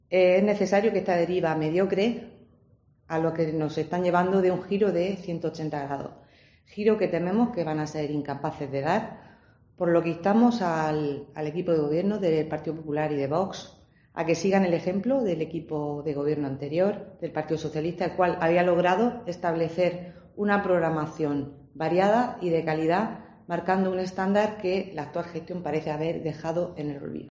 María Ángeles Mazuecos, edil del PSOE